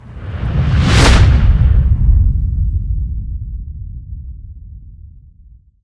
gravi_blowout1.ogg